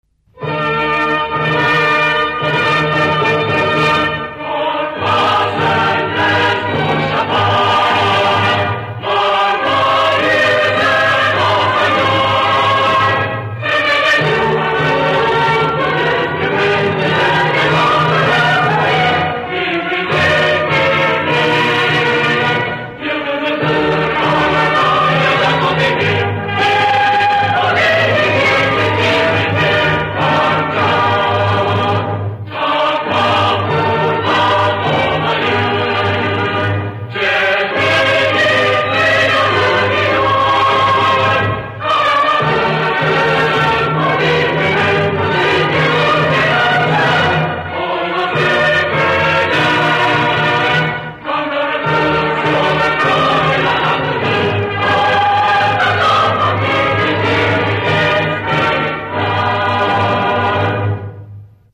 İstiklal Marşımız -Sözlü-Bando Eşliğinde
istiklalmarsisozlu.mp3